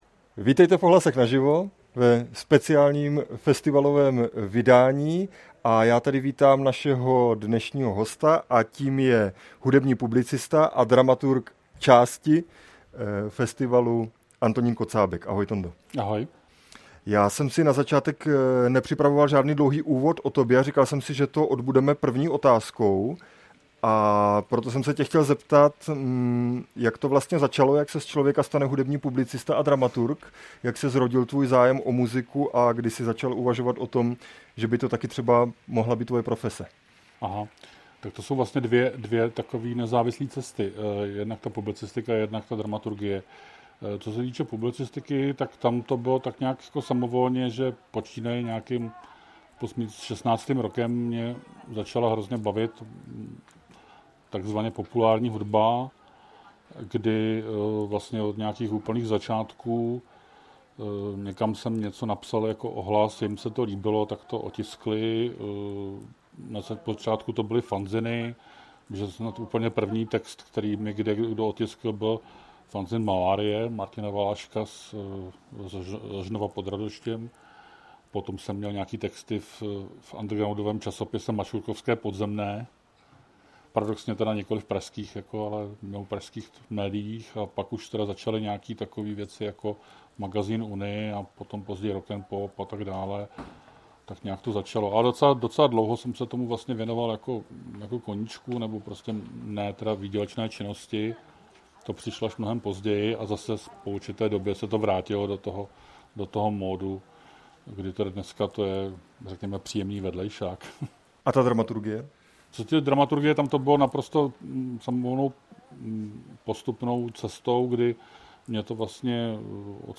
Tématem byla dramaturgie festivalu, její pestrost, ale i limity, napětí mezi popem a alternativou a pohled na českou hudební scénu. Během natáčení nám dvakrát vypadla elektřina, takže prosím omluvte jeden dva divočejší střihy :)
Živý rozhovor